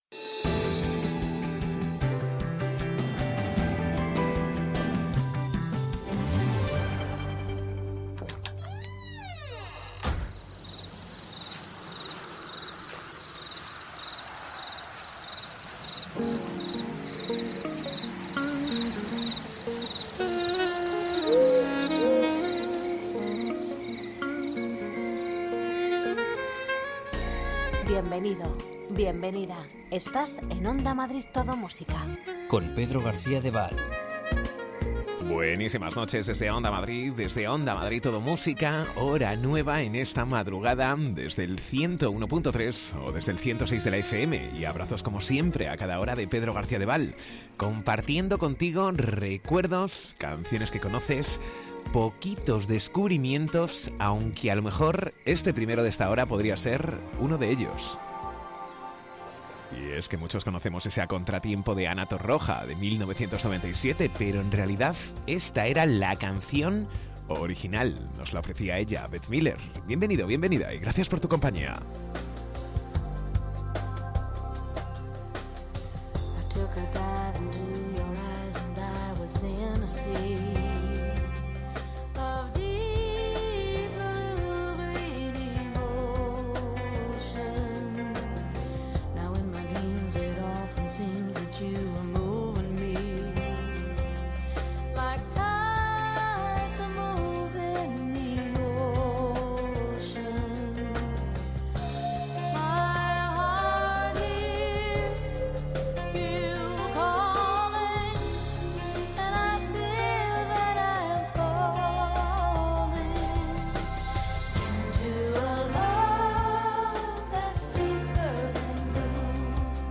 Ritmo tranquilo, sosegado, sin prisas... Las canciones que formaron parte de la banda sonora de tu vida tanto nacionales como internacionales las rescatamos del pasado durante la madrugada.